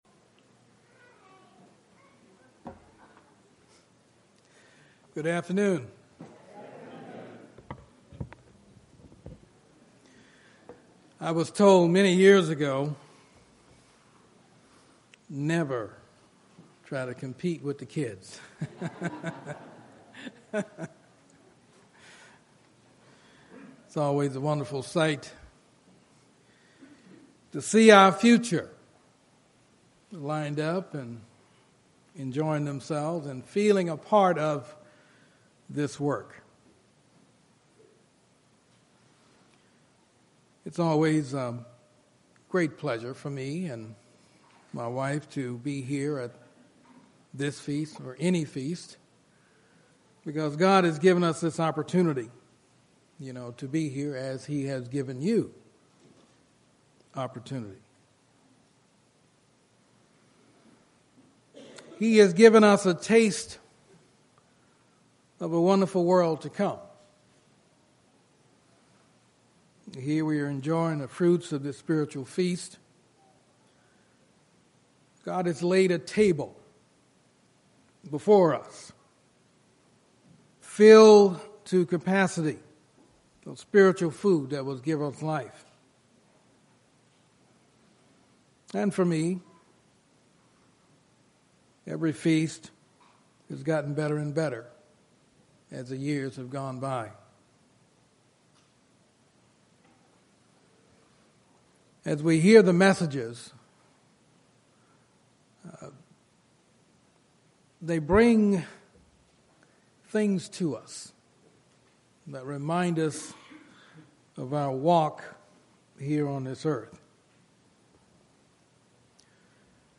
This sermon was given at the Phoenix, Arizona 2016 Feast site.